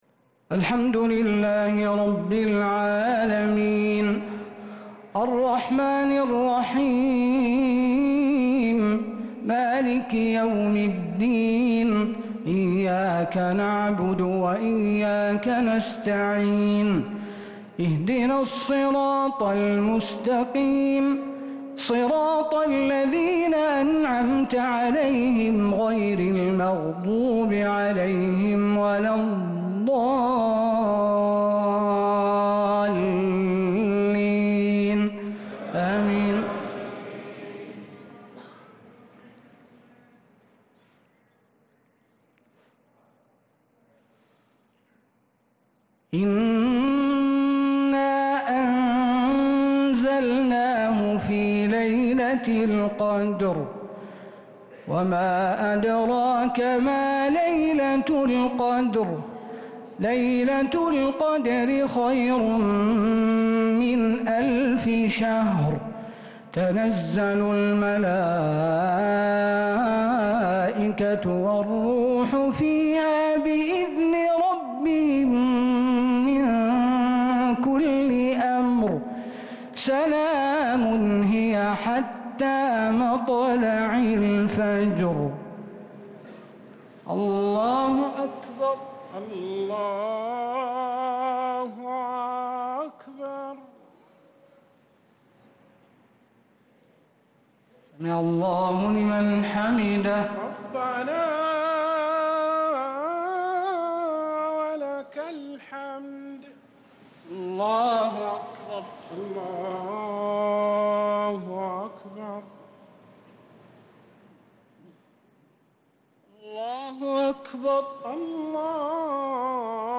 الشفع والوتر ليلة 18 رمضان 1446هـ | Witr 18th night Ramadan 1446H > تراويح الحرم النبوي عام 1446 🕌 > التراويح - تلاوات الحرمين